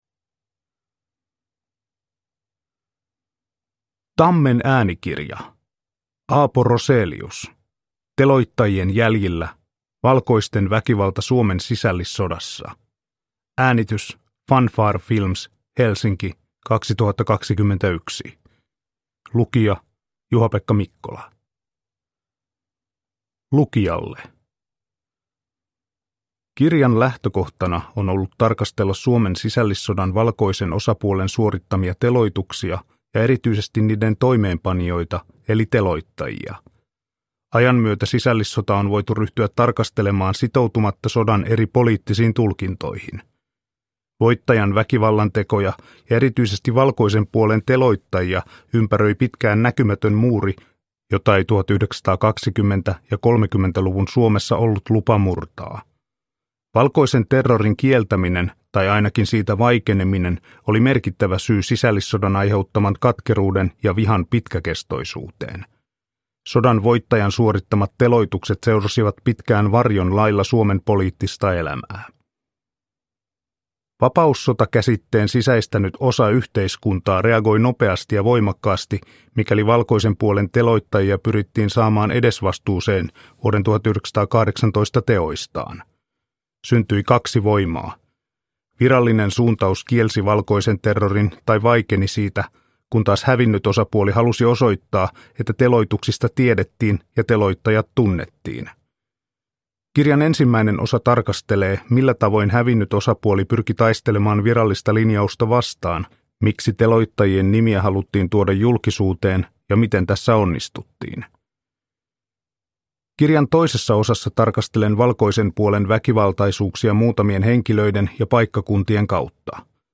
Teloittajien jäljillä – Ljudbok – Laddas ner